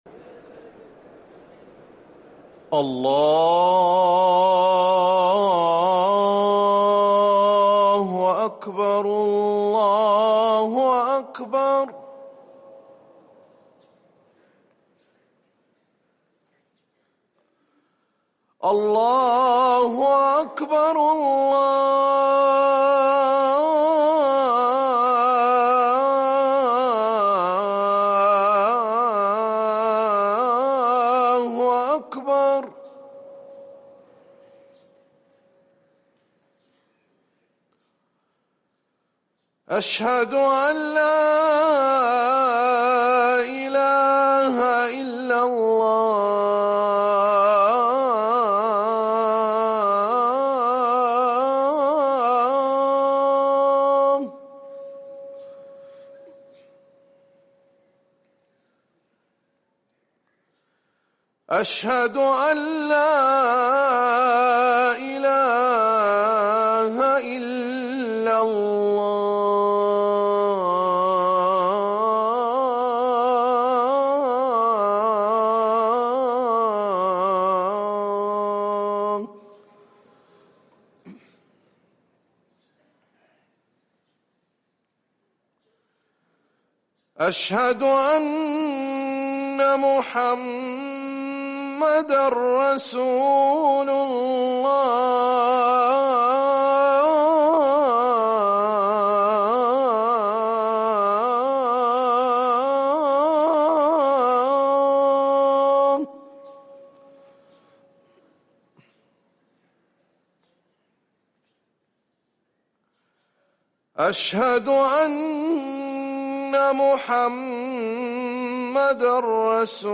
أذان الفجر للمؤذن